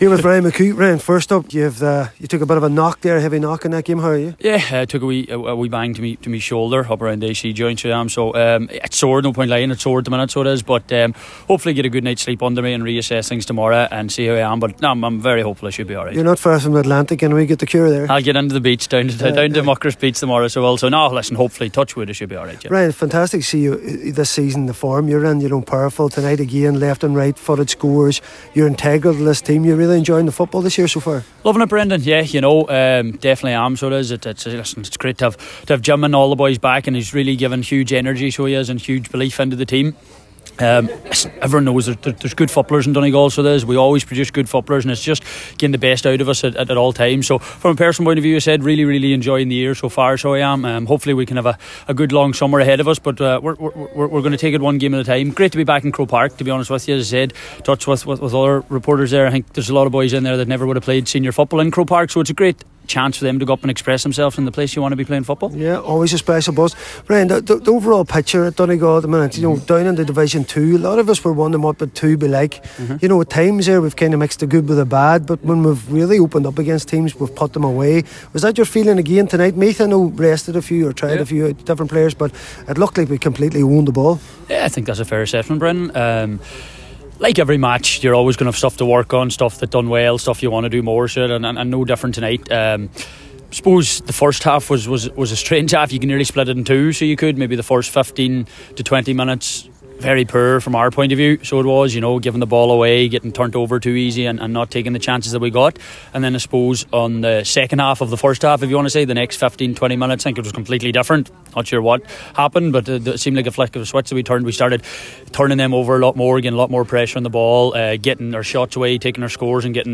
after full time in MacCumhaill Park…